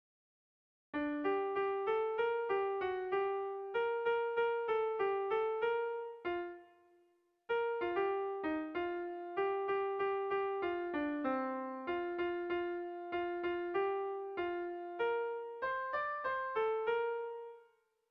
Gabonetakoa
Lauko txikia (hg) / Bi puntuko txikia (ip)
AB